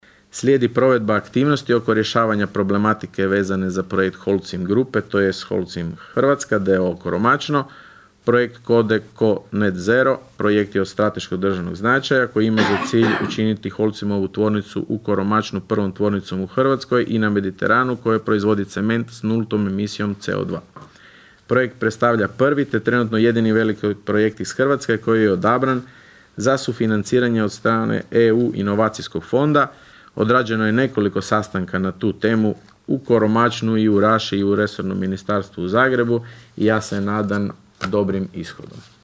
Na sinoćnjoj sjednici Općinskog vijeća Raše, općinski načelnik Leo Knapić podnio je izvješće o radu za prvih šest mjeseci 2025. godine.
ton – Leo Knapić 2).